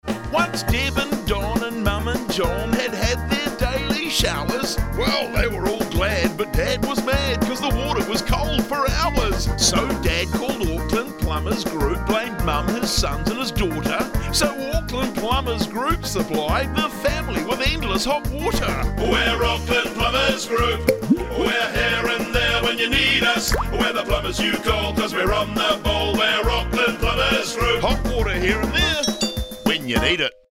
We’re plumbers, not singers – but we had fun with this one.